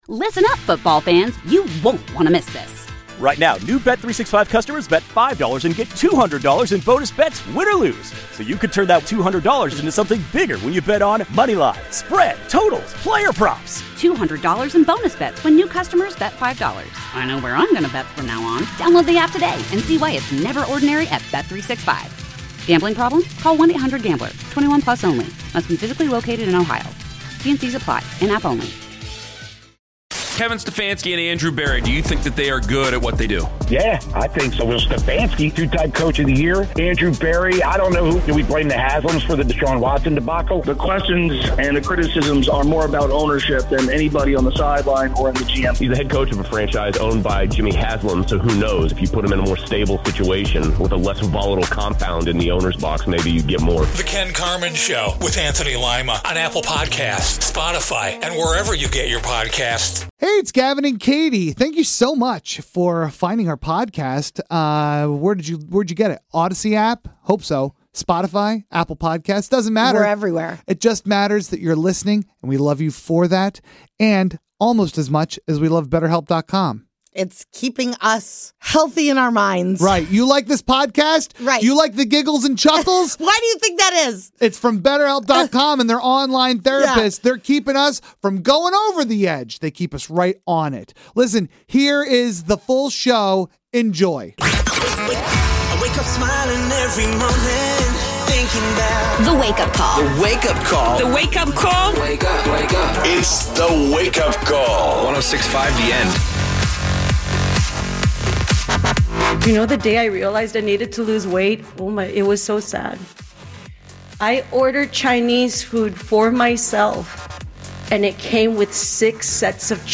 The Wake Up Call is a morning radio show based in Sacramento, California, and heard weekday mornings on 106.5 the End.